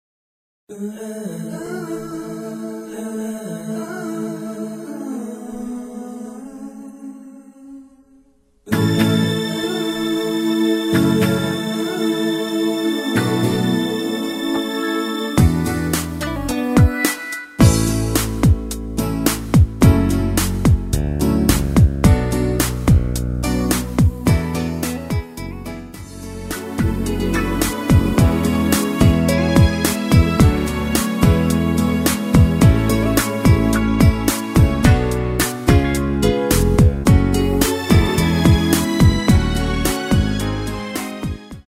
키 A 가수
원곡의 보컬 목소리를 MR에 약하게 넣어서 제작한 MR이며